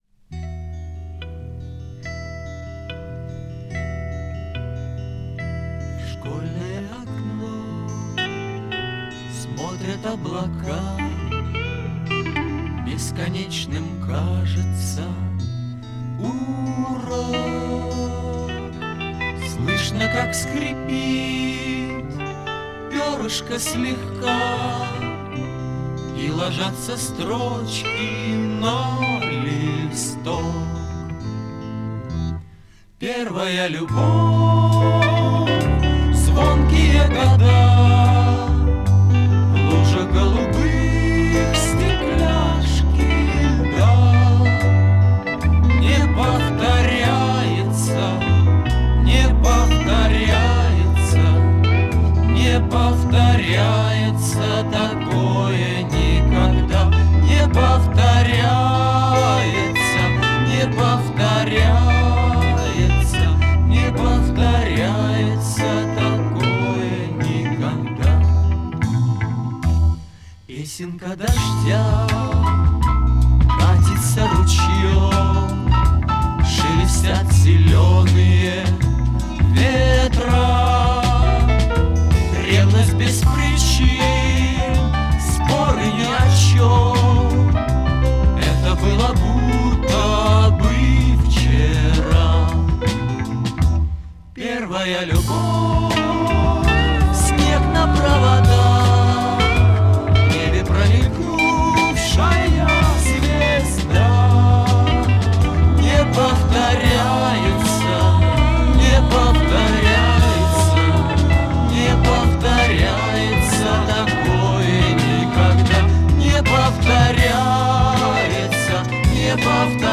ВИА СССР